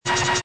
Drop Zzzz SFX